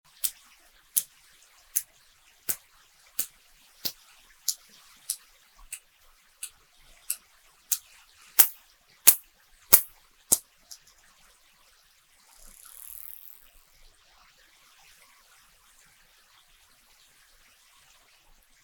The passive acoustics team used a towed hydrophone array to detect and record marine mammal vocalizations in waters deeper than 100 meters.
The acoustic team also captured audio of sperm whales using echolocation clicks to search for and find prey.
[Sperm whale audio clip](mp3)
Sperm whale clip of an individual searching and finding prey. Rapid clicking that sounds like a fluttery sound indicates that the whale is honing in on prey.